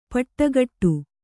♪ paṭṭagaṭṭu